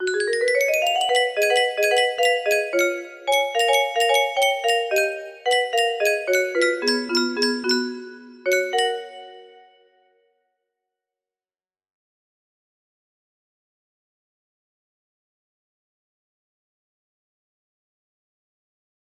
Little music demo music box melody